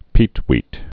(pētwēt)